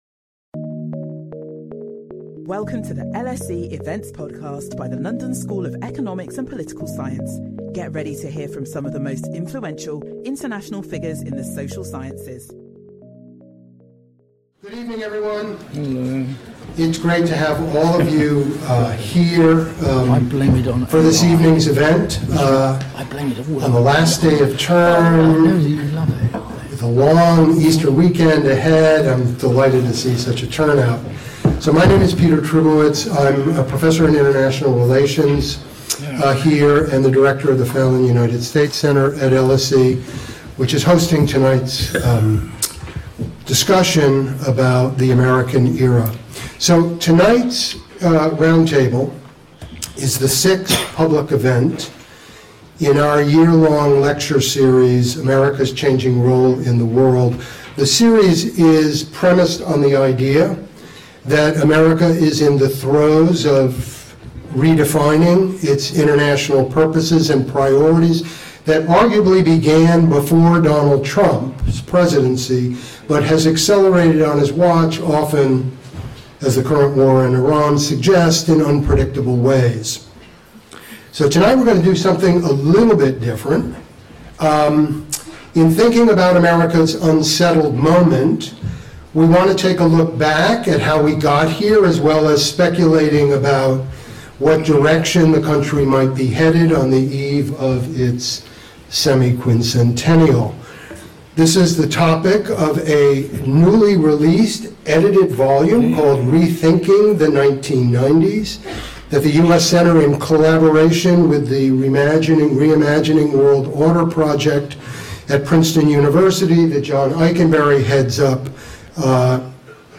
At a time of intensifying geopolitical rivalry, economic nationalism, and ideological extremism, this roundtable brings together a group of leading political scientists and historians to take stock of the choices and pathways that have brought America and the world to this unsettled moment.